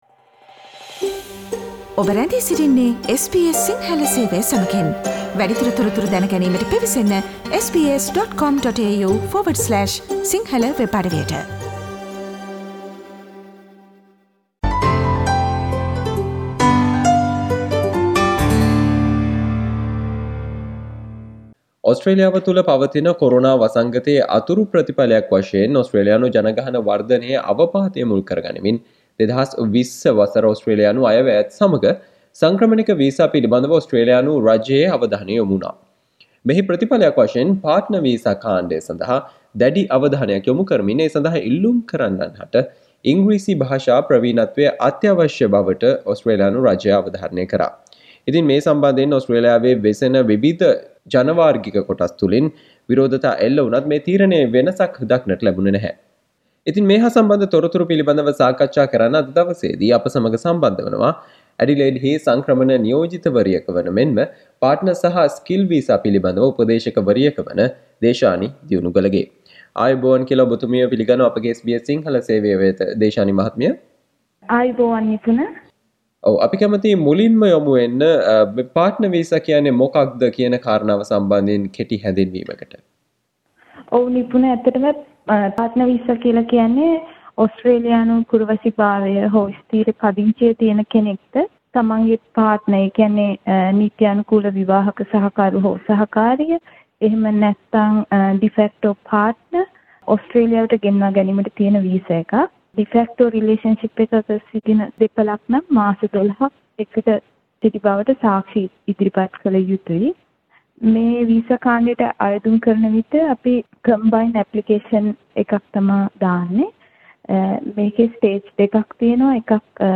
සාකච්චාව